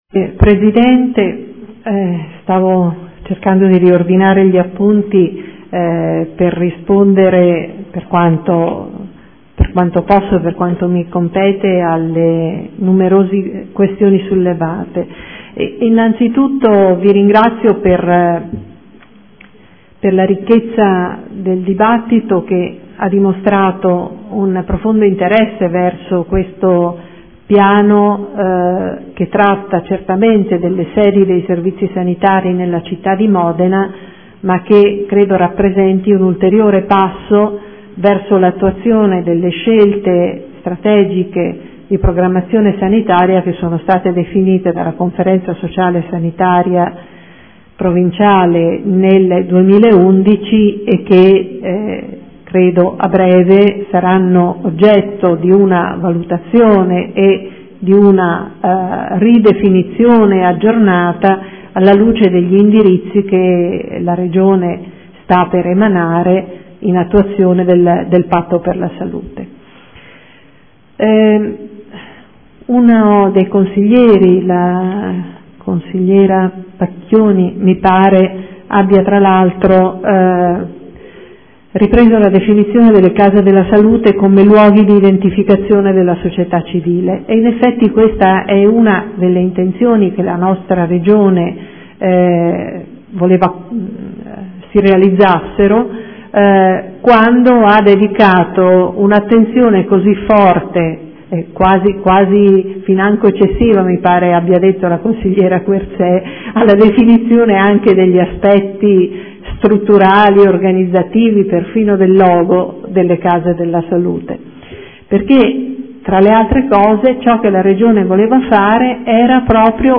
Mariella Martini - Direttore Generale AUSL Modena — Sito Audio Consiglio Comunale
Seduta del 3/11/2014. Dibattito su ordini del giorno